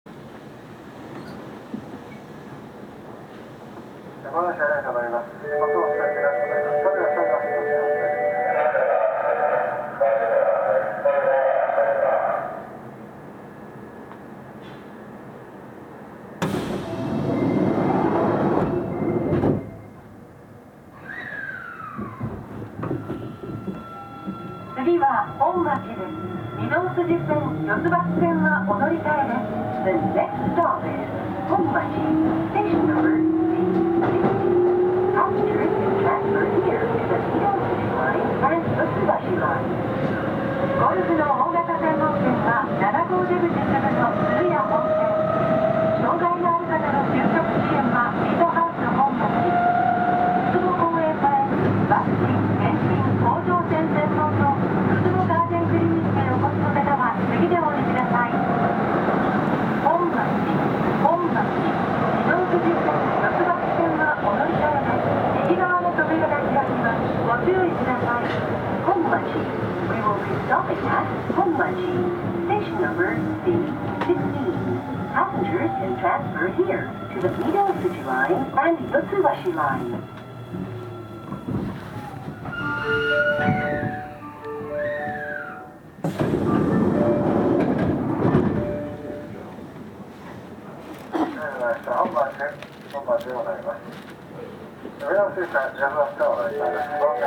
なお、VVVFインバータは後に更新されており、素子がIGBTに変更となっています。
走行音
録音区間：堺筋本町～本町(お持ち帰り)